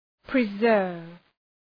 {prı’zɜ:rv}